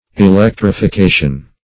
Electrification \E*lec`tri*fi*ca"tion\, n. (Physics)